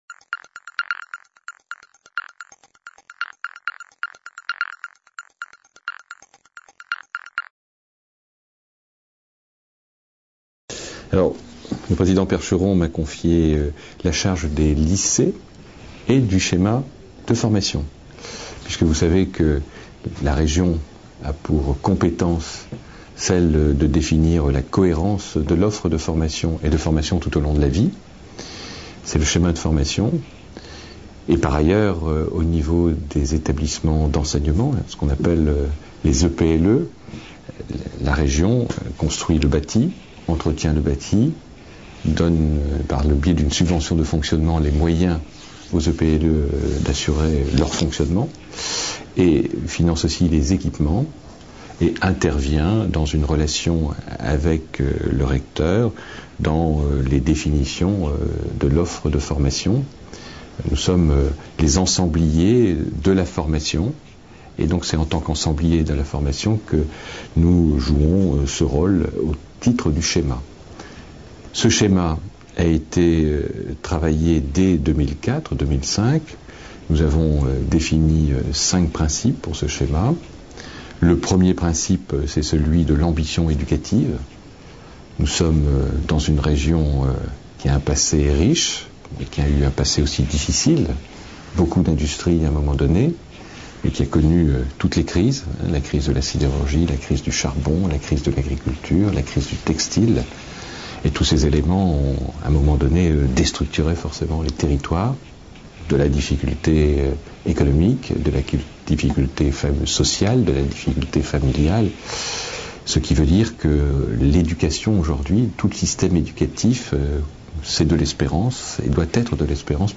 Entretien avec Monsieur Philippe Kémel au Conseil régional Nord-Pas-de-Calais, en 2009 | Canal U
Nous avons rencontré Monsieur Philippe Kémel au Conseil régional Nord-Pas-de-Calais à Lille. Monsieur Philippe Kemel était alors vice-président, chargé de l'apprentissage, au Conseil régional Nord-Pas-de-Calais.